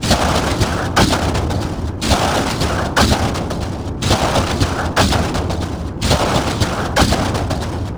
Abstract Rhythm 02.wav